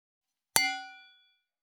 315金属製のワインカップ,ステンレスタンブラー,シャンパングラス,ウィスキーグラス,ヴィンテージ,ステンレス,金物グラス,
効果音厨房/台所/レストラン/kitchen食器